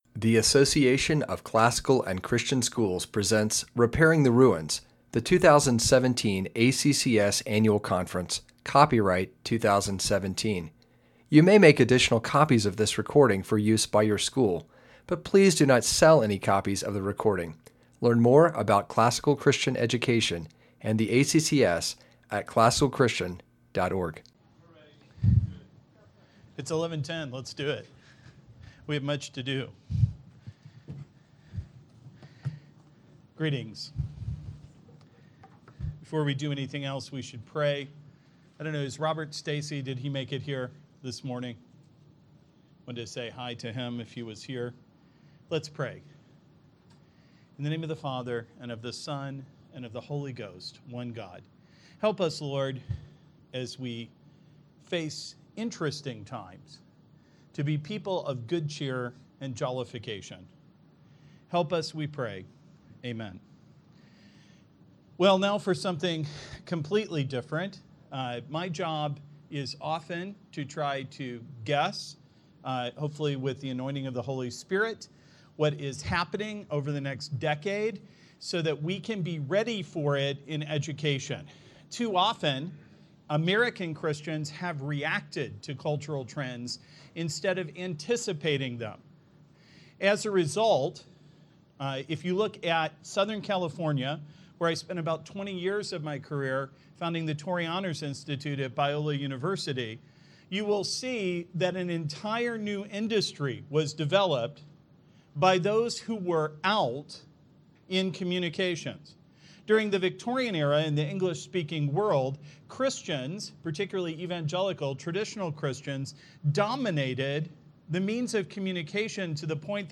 2017 Workshop Talk | 0:55:08 | All Grade Levels, Virtue, Character, Discipline